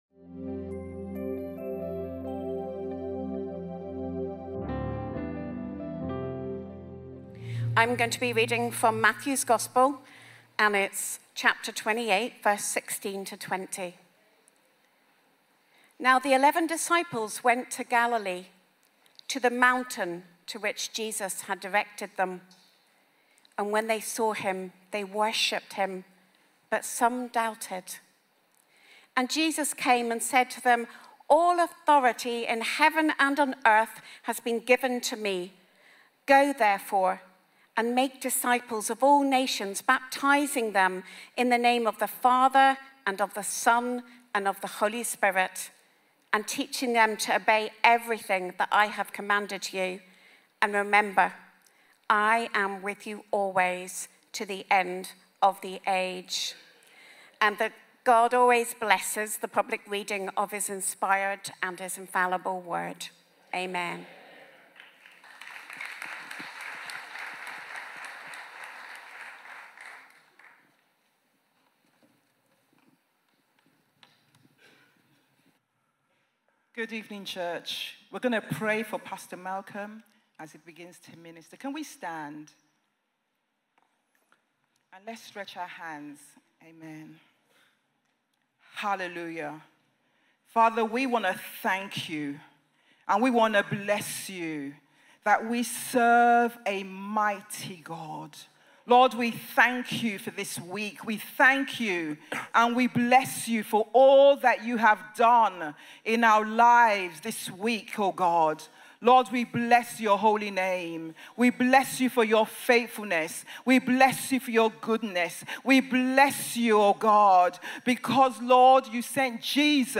Responsive Conference